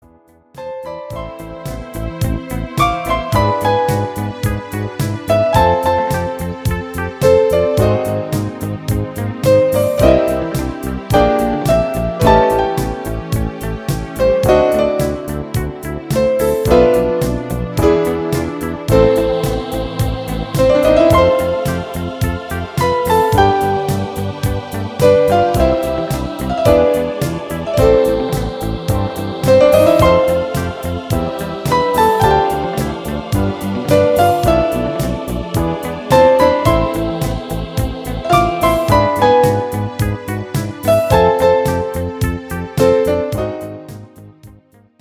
instrumentale Dinnermusik